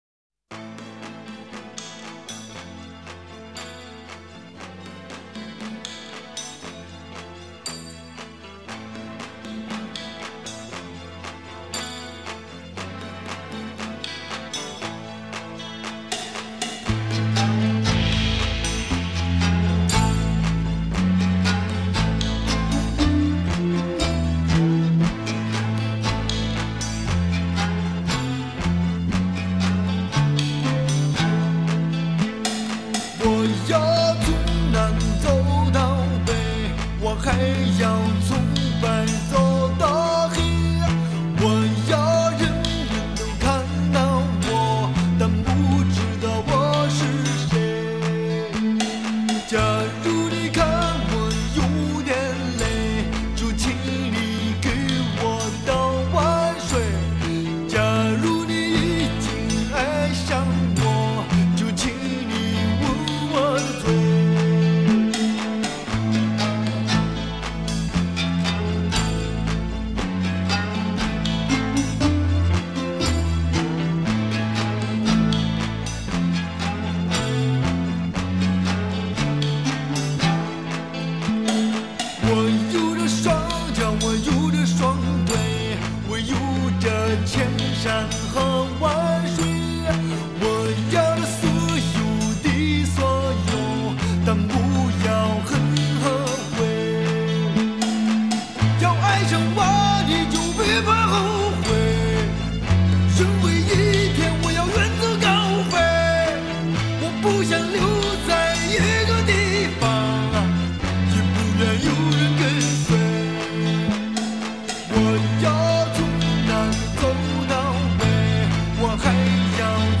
Ps：在线试听为压缩音质节选，体验无损音质请下载完整版 我要从南走到北, 我还要从白走到黑.